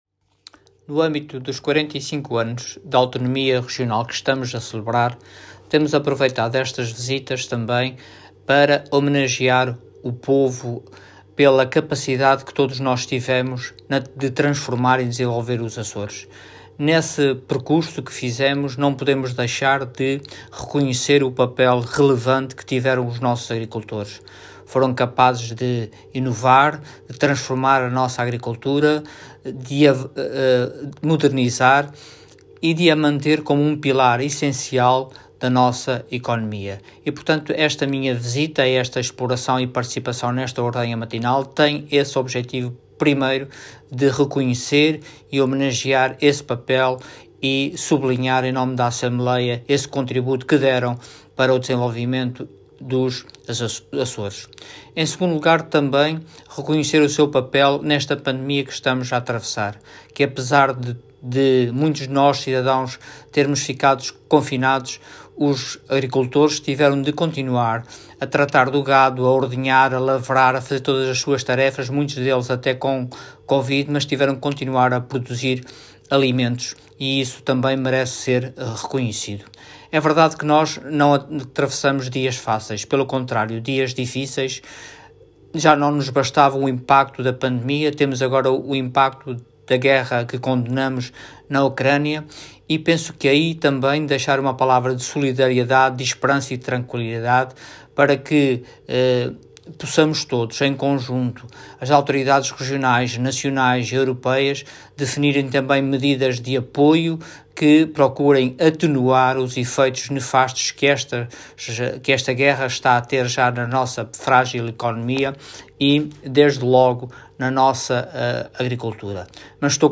Detalhe de vídeo 23 de março de 2022 Download áudio Download vídeo XII Legislatura Visita do Presidente da ALRAA à Graciosa Intervenção Orador Luís Garcia Cargo Presidente da Assembleia Regional Entidade ALRAA